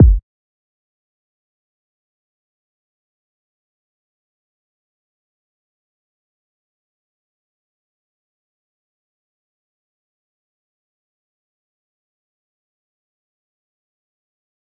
DMV3_Kick 8.wav